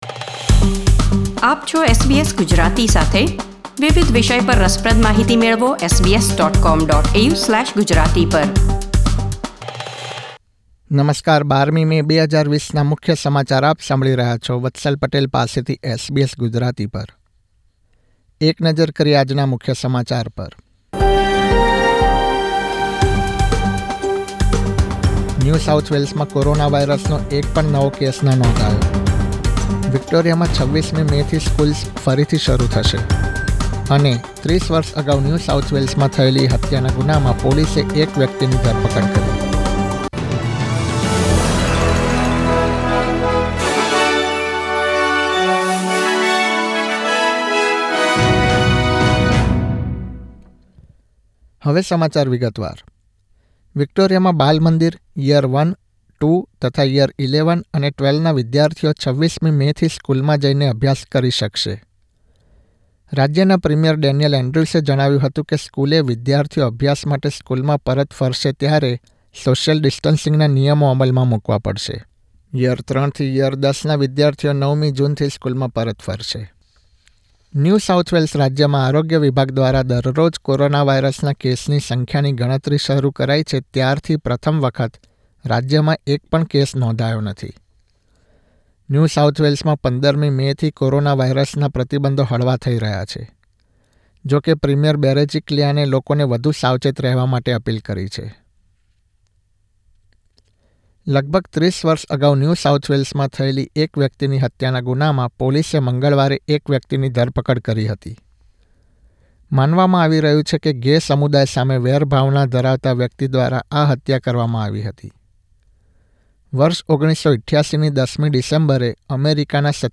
SBS Gujarati News Bulletin 12 May 2020